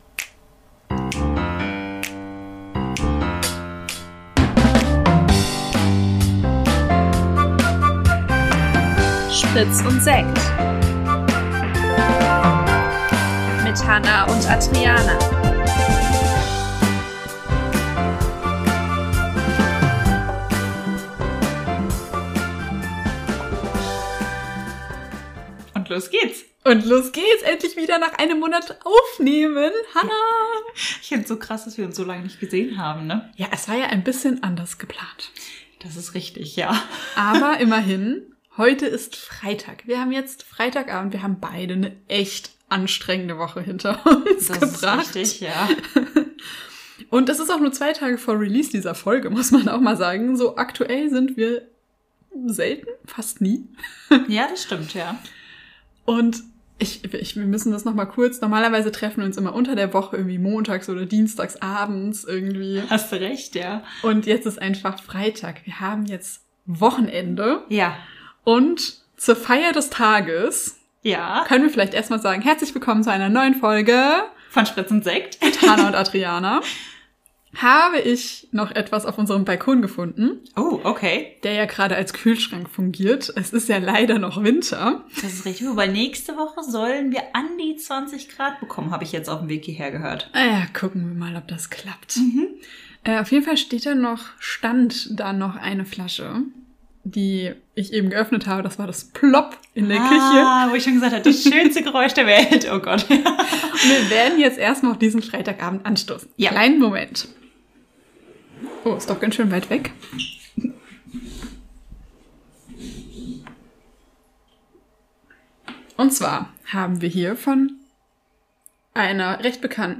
Zwei junge Frauen, (noch) Ende 20, die sich alle zwei Wochen zusammensetzen und über Gott und die Welt reden. Geleitet von einem bestimmten Thema diskutieren wir über recherchierte Fakten und lassen die ein oder andere persönliche Geschichte einfließen.